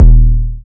808 (Powder).wav